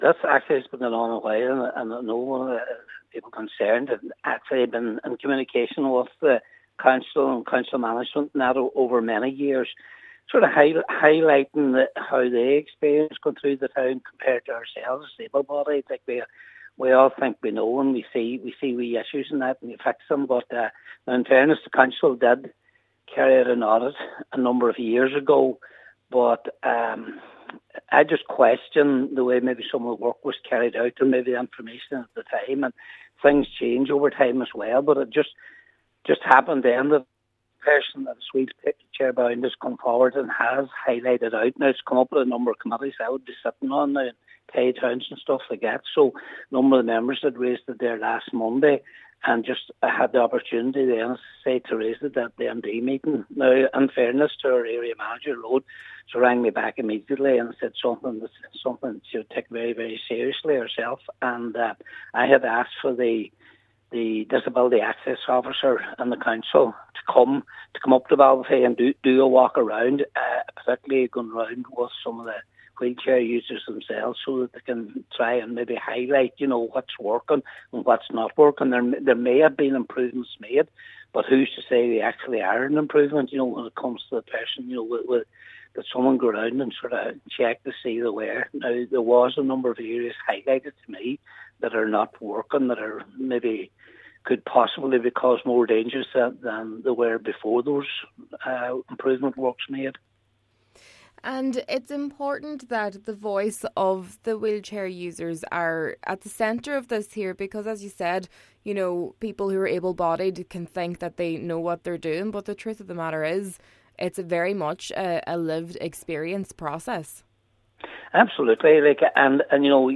Cllr McGowan says he feels the matter has been taken seriously by the council: